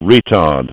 IDG-A32X / Sounds / GPWS / retard.wav
retard.wav